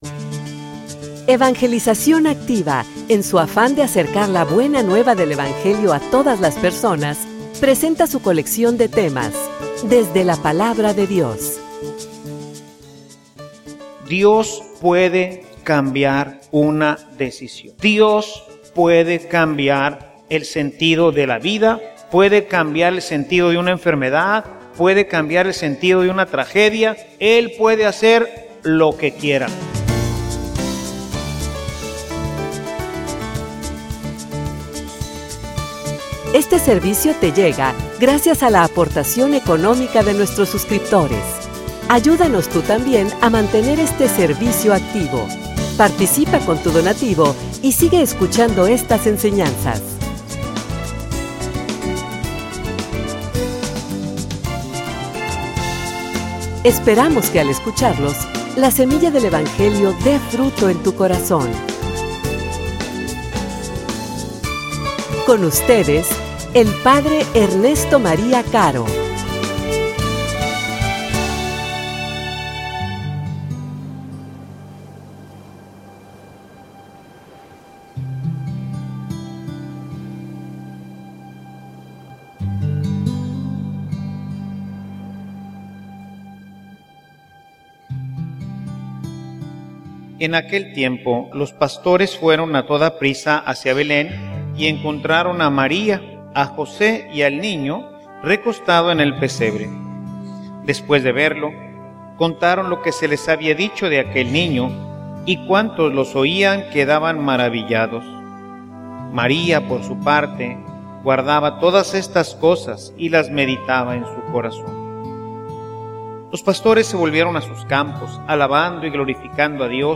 homilia_Una_madre_que_intercede.mp3